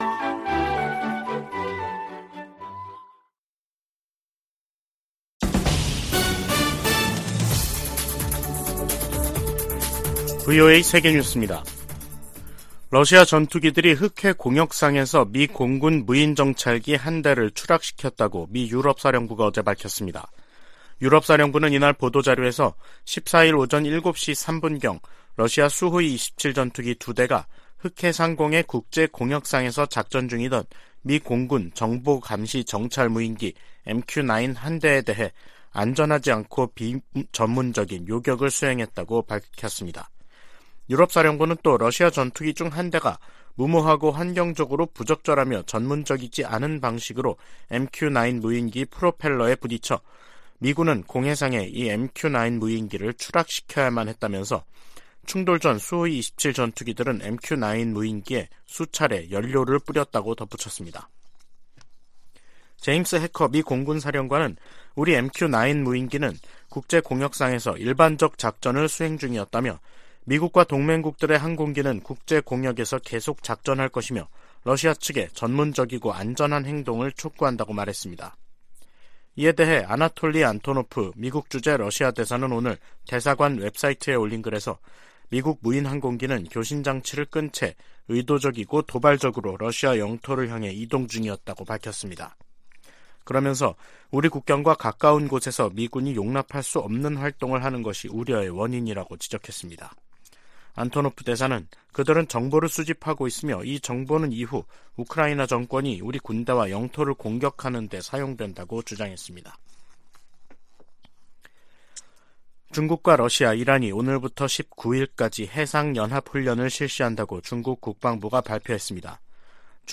VOA 한국어 간판 뉴스 프로그램 '뉴스 투데이', 2023년 3월 15일 2부 방송입니다. 북한은 14일 황해남도 장연에서 지대지 탄도미사일 2발 사격 훈련을 실시했다고 다음날 관영매체를 통해 발표했습니다.